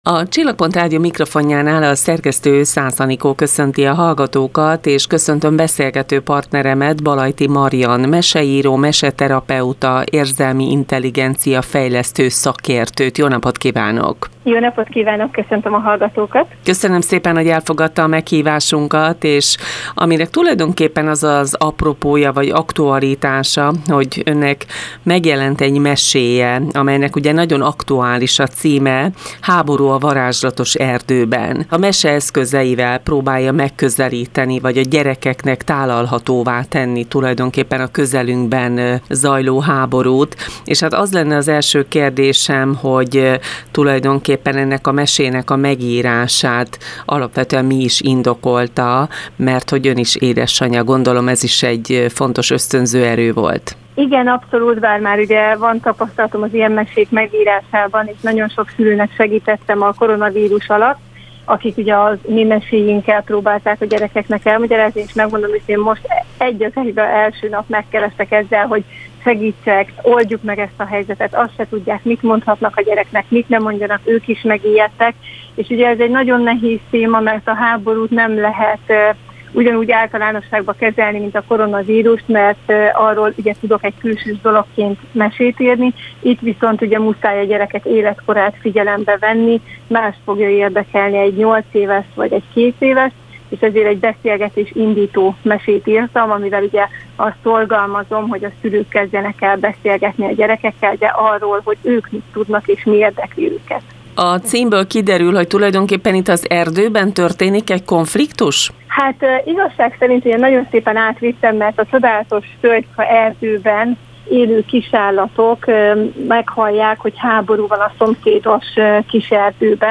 hangoskönyv formájában is meghallgathatnak.